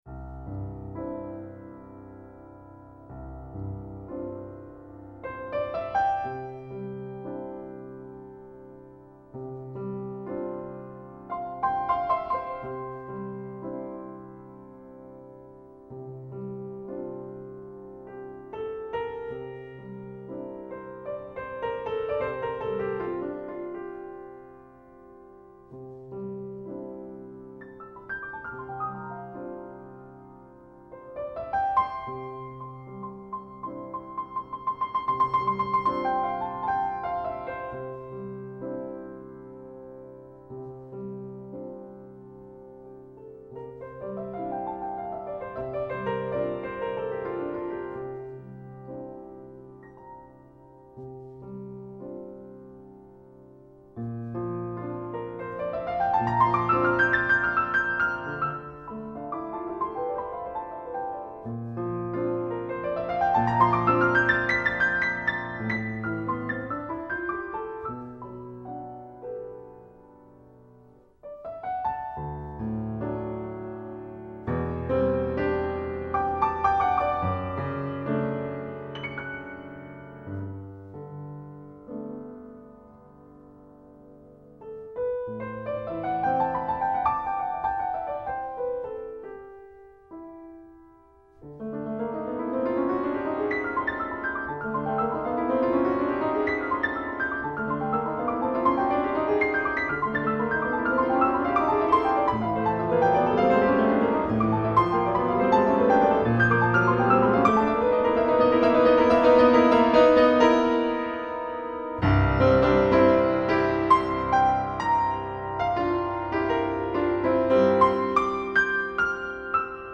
for Piano (1983)
piano.